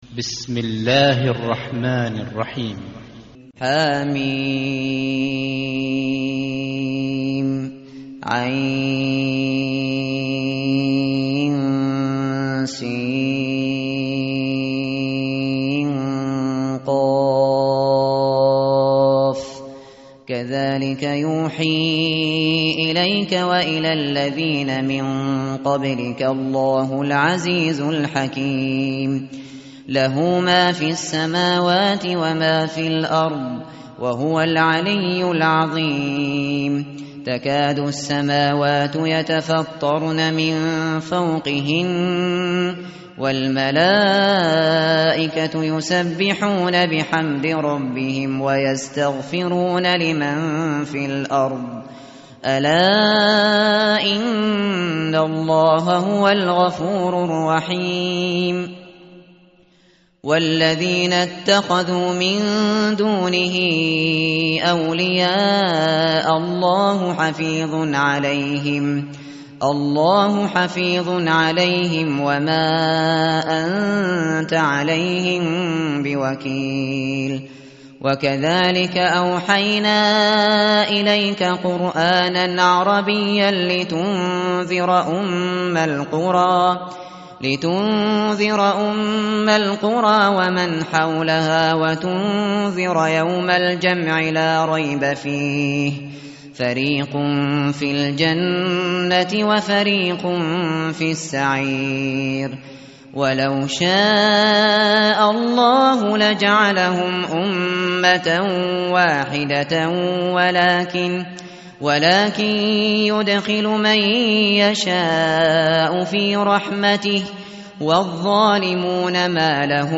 متن قرآن همراه باتلاوت قرآن و ترجمه
tartil_shateri_page_483.mp3